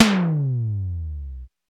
TOM XC.TOM06.wav